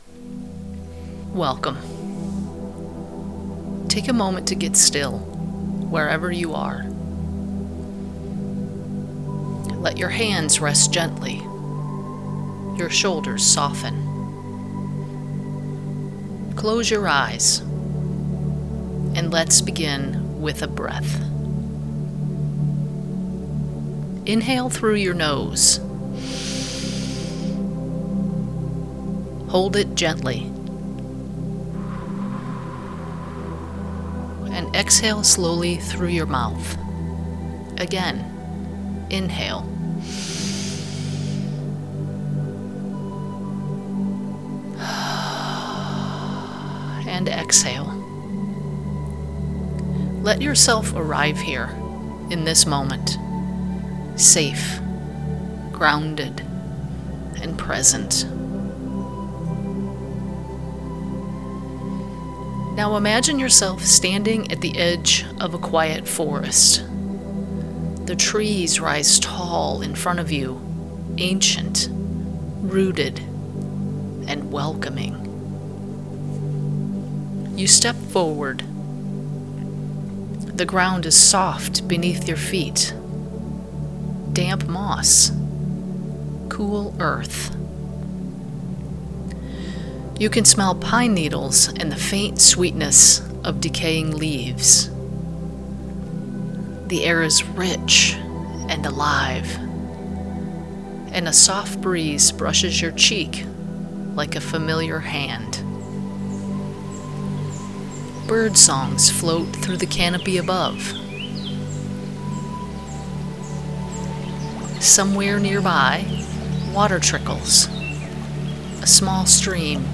New Guided Meditation: A Walk in the Woods (from Wherever You Are)